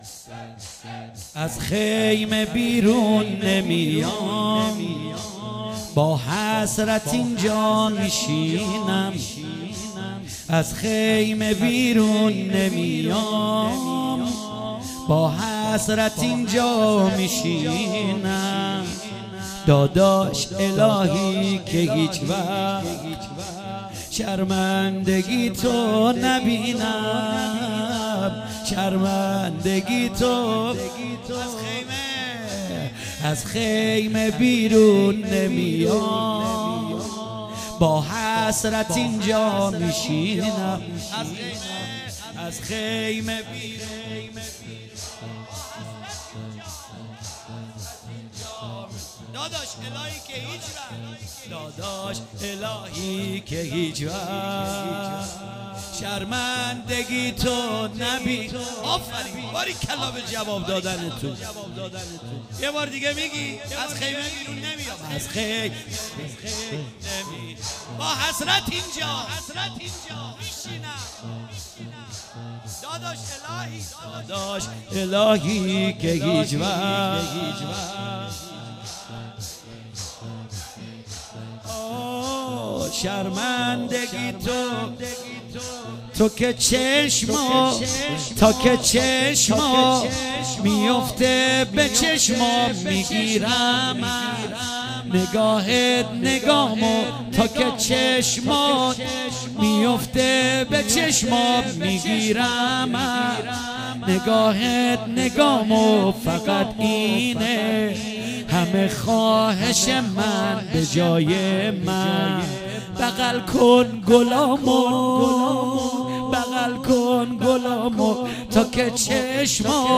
شب چهارم محرم 97 - زمینه - از خیمه برون نمیام